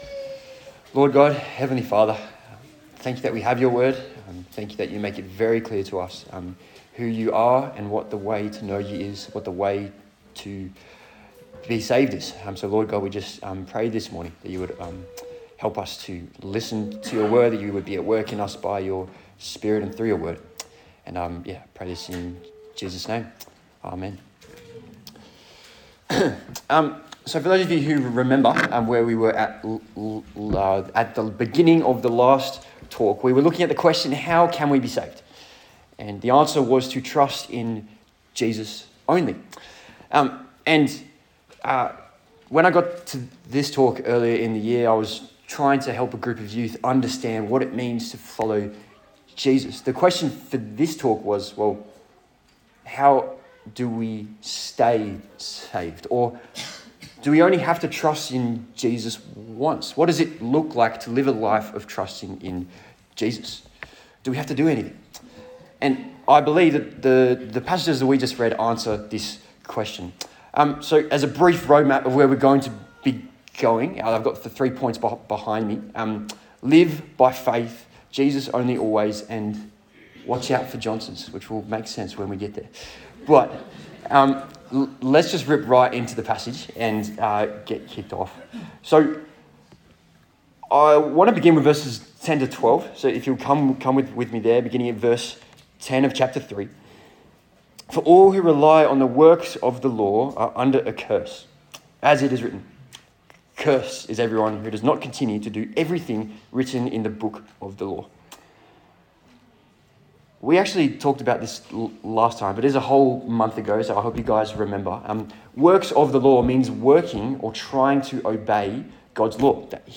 Galatians Passage: Galatians 3:1-14 Service Type: Sunday Service